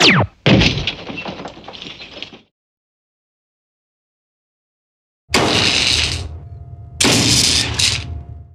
Lasergun Sound
cartoon